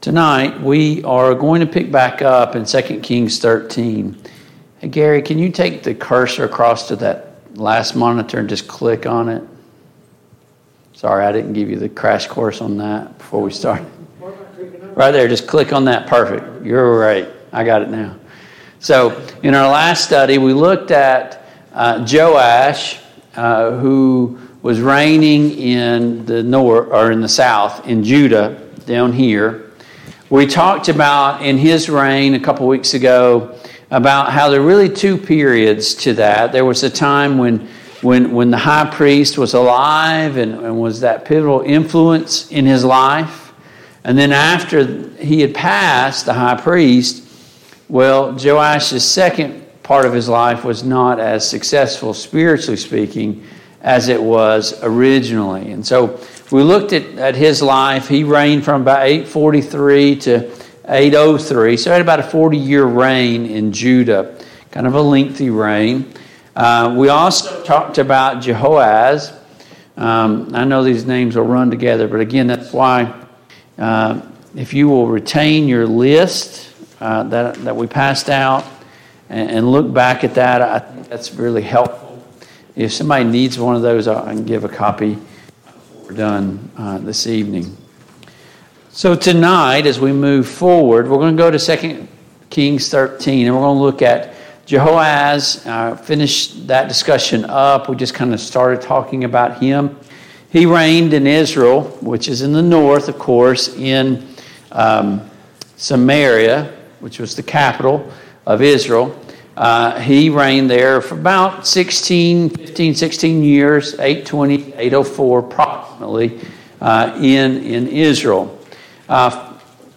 The Kings of Israel and Judah Passage: 2 Kings 13, 2 Kings 14, 2 Chronicles 24, 2 Chronicles 25 Service Type: Mid-Week Bible Study